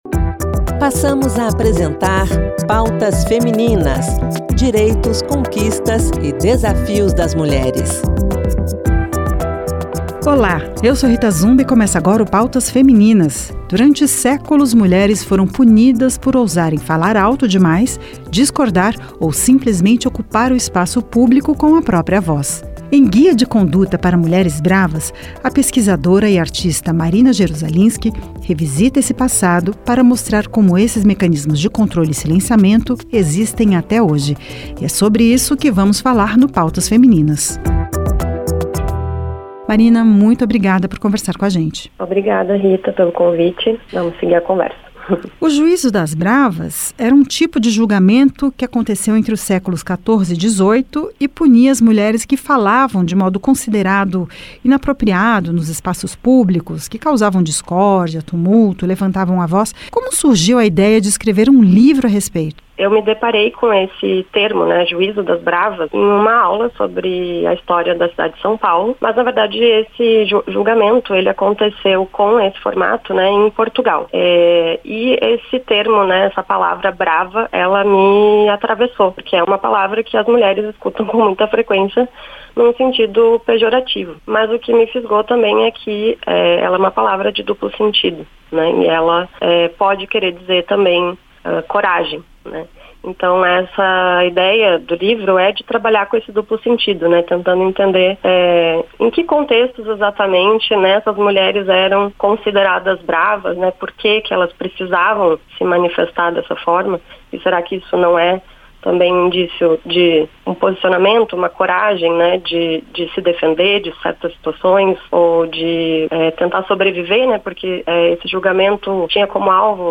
Para falar do tema, o programa recebe a artista e pesquisadora